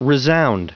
Prononciation du mot resound en anglais (fichier audio)
Prononciation du mot : resound